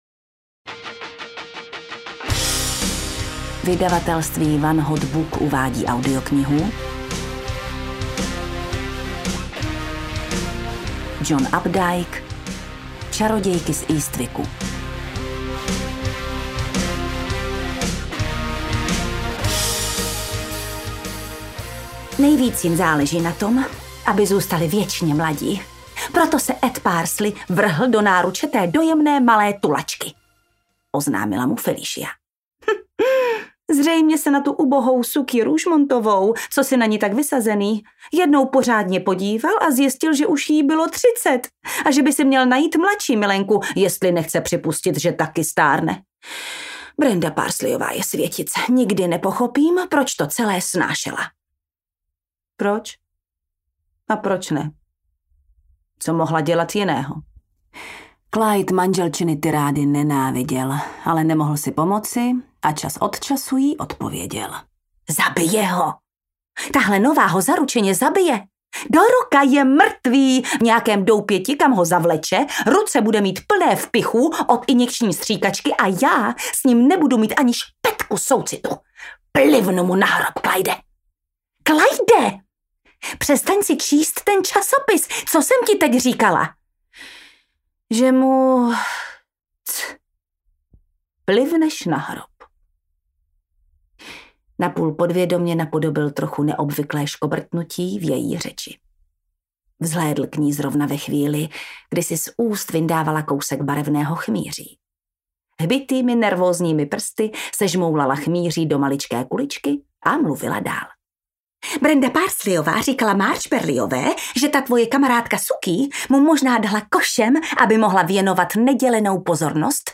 Čarodějky z Eastwicku audiokniha
Ukázka z knihy
• InterpretJana Stryková